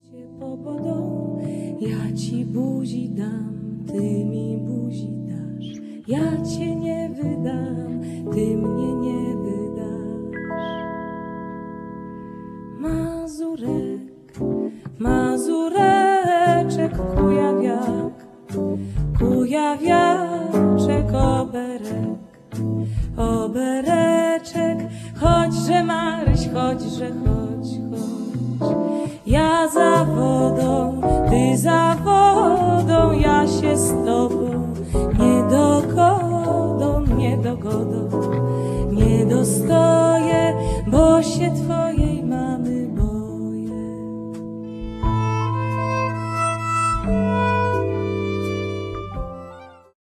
Nagranie wielośladowe, cyfrowe, "live"
śpiew, skrzypce
kontrabas
altówka, fender piano
perkusja
oprócz utworu 11 tradycyjna.